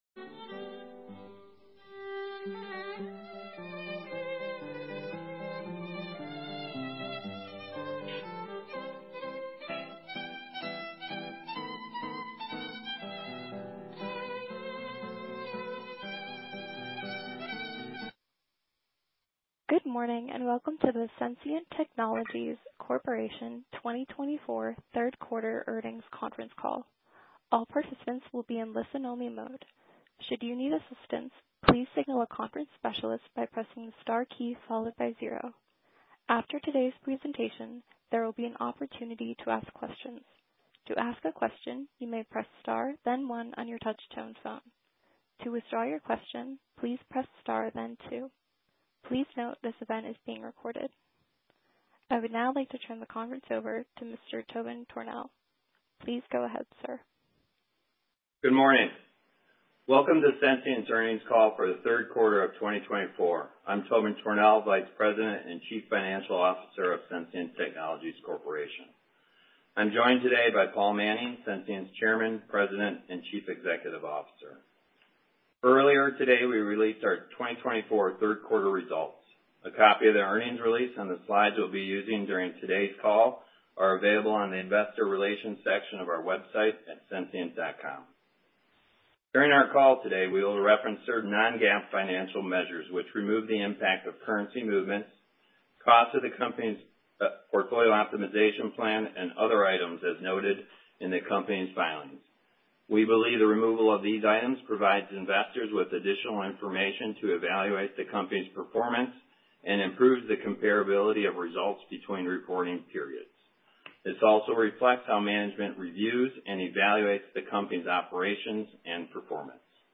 Sensient Technologies Corporation - Third Quarter 2024 Earnings Release & Conference Call